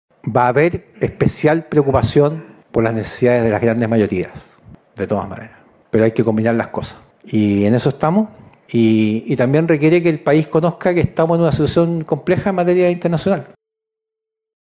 De hecho, el ministro Quiroz sostuvo que “el país conozca que estamos en una situación compleja a nivel internacional”.